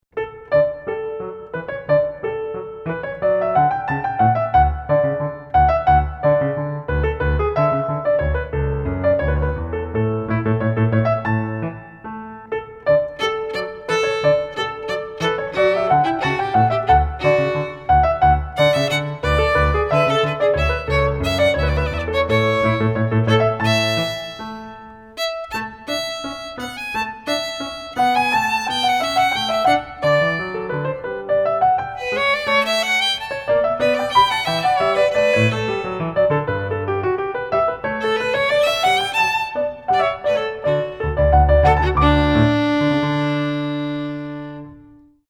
(piano)
flute - trumpet -
violin - cello